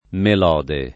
vai all'elenco alfabetico delle voci ingrandisci il carattere 100% rimpicciolisci il carattere stampa invia tramite posta elettronica codividi su Facebook melodo [ mel 0 do ] (meglio che melode [ mel 0 de ]) s. m. (stor.